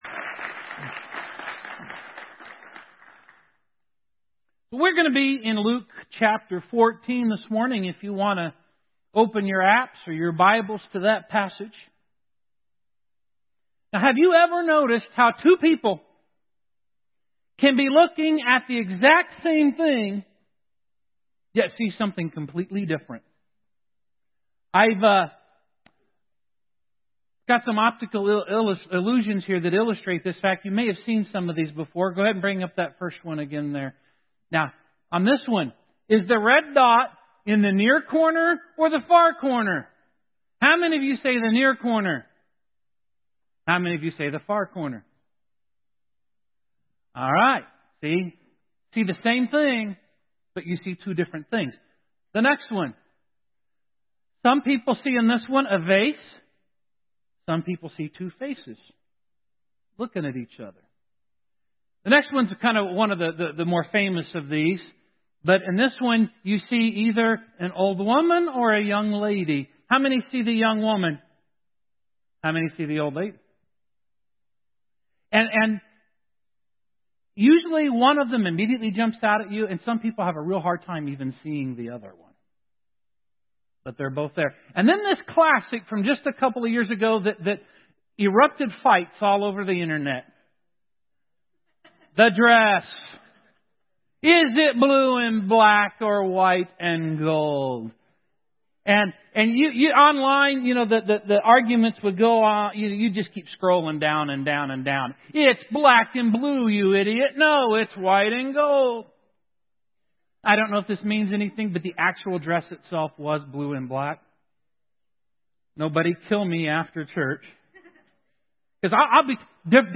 Speech Loving Like Jesus Audio Sermon Save Audio Save PDF Jesus sees.